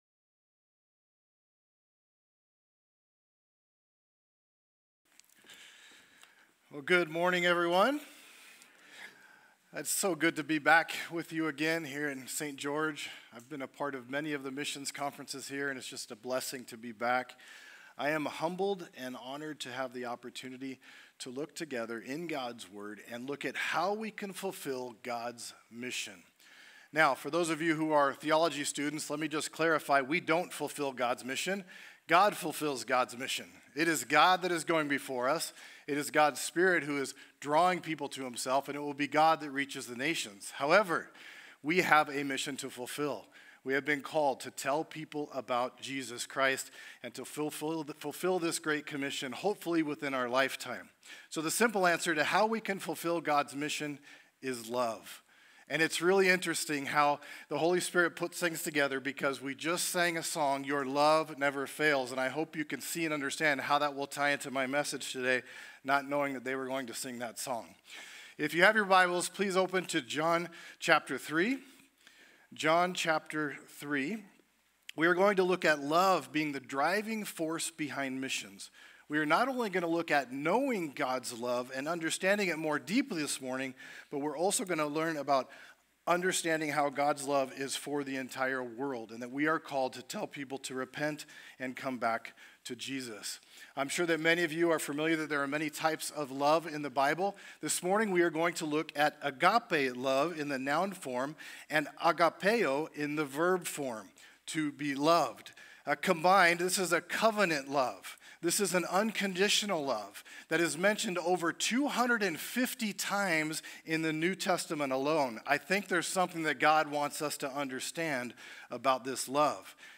Calvary Chapel Saint George - Sermon Archive
From Series: "Missions Conference 2025"